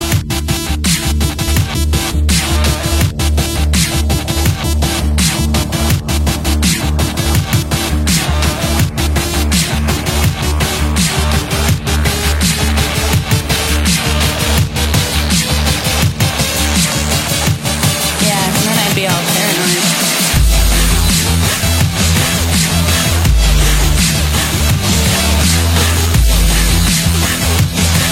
Kategorie Remixy